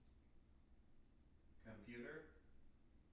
wake-word
tng-computer-223.wav